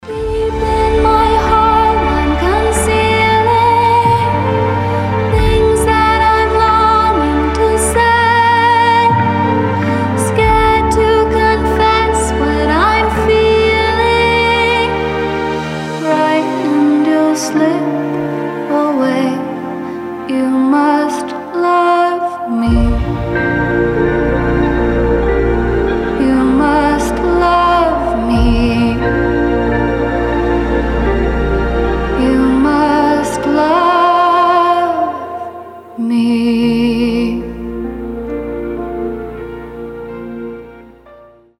• Качество: 320, Stereo
спокойные
chillout
красивый женский голос
расслабляющие
ballads
нежные
успокаивающие